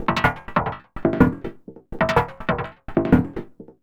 tx_perc_125_garbagecans2.wav